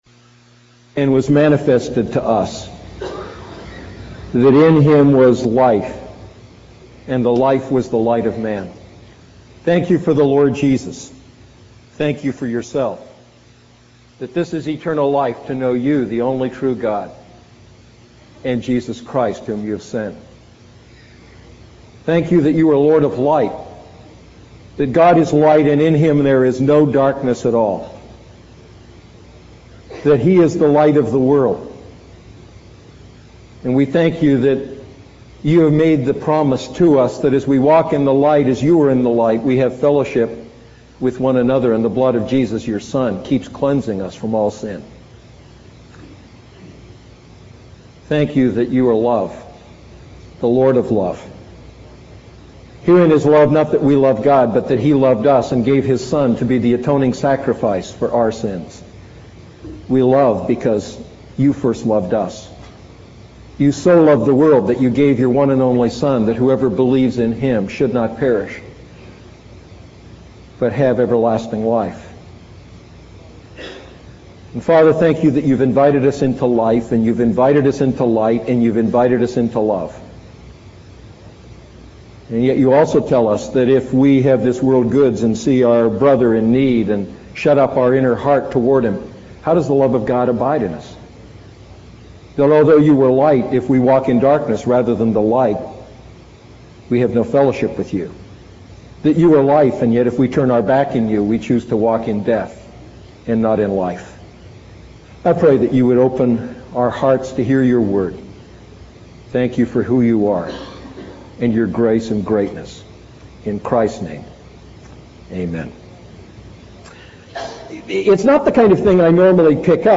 A message from the series "I John Series."